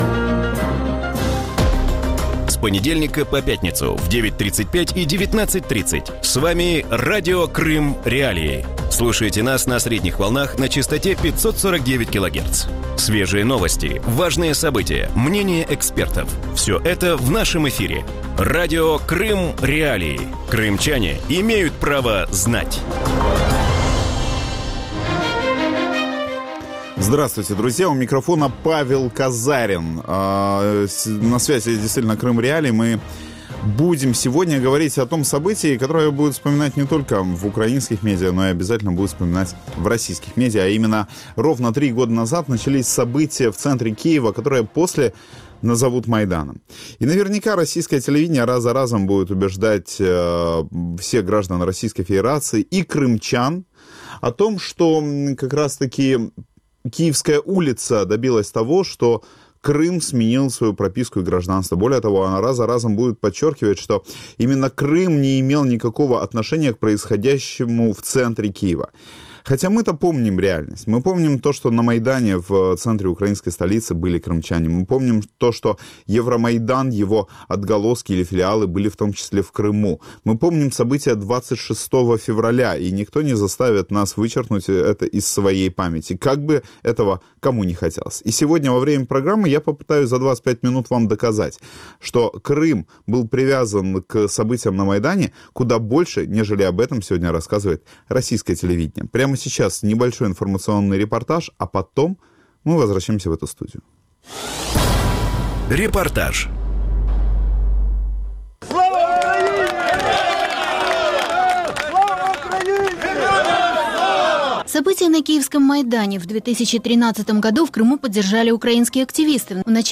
В вечернем эфире Радио Крым.Реалии говорят о годовщине начала Евромайдана в Киеве и в Симферополе. Каким был Евромайдан в Крыму, за что боролись активисты и почему протест разделил полуостров?